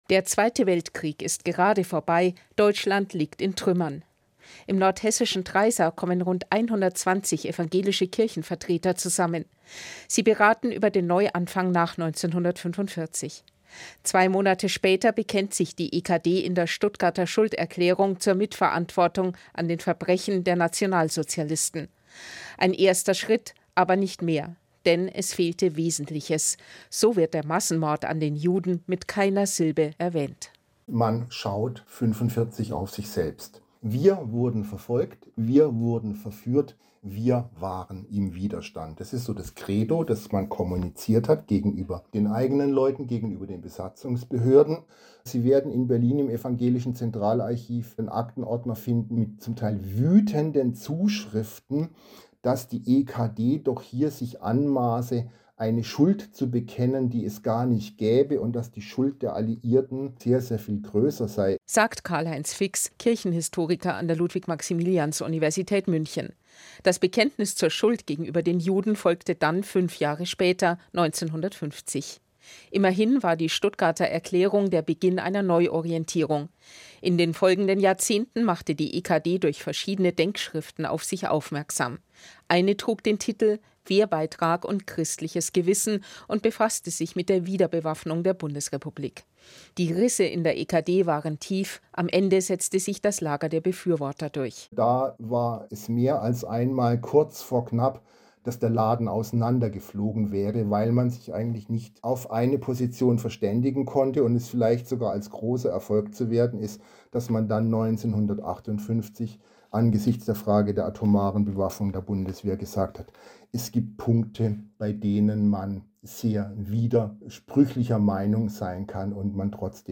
Beitrag des Bayerischen Rundfunks am 1. September 2025 zum 80. Jahrestag der Kirchenversammlung von Treysa.
Mit Statements